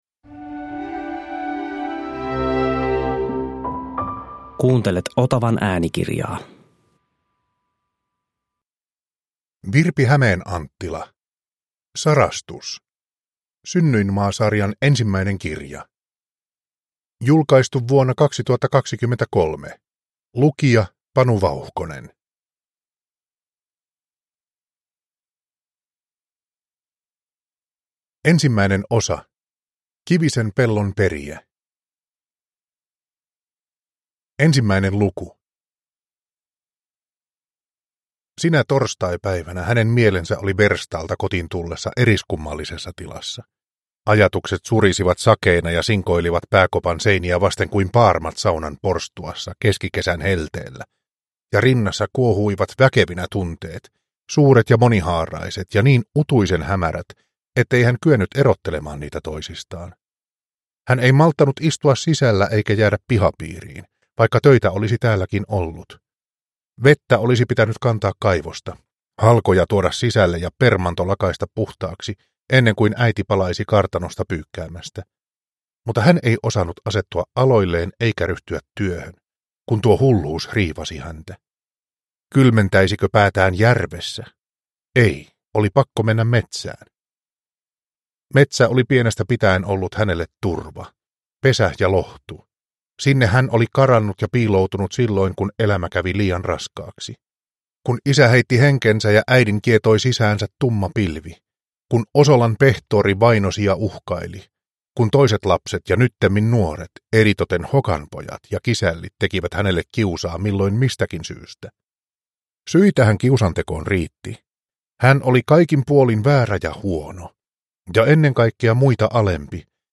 Sarastus – Ljudbok – Laddas ner